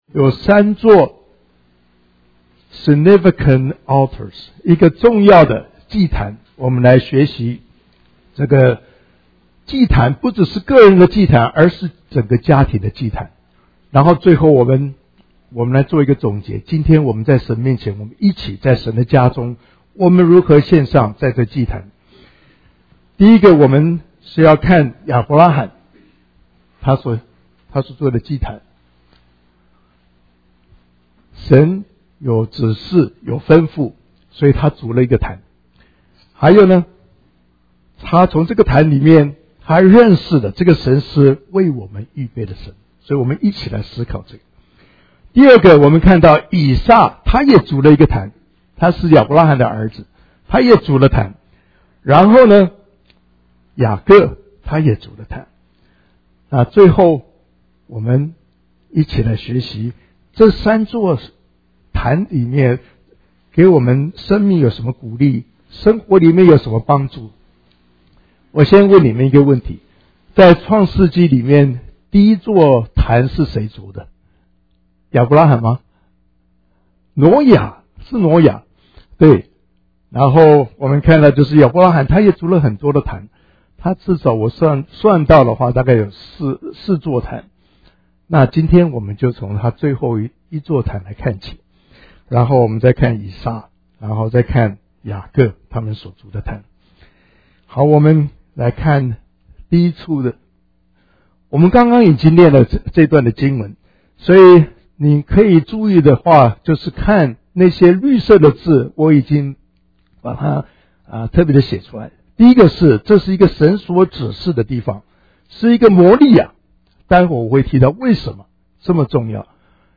Sermon Podcasts Downloads | Greater Kansas City Chinese Christian Church (GKCCCC)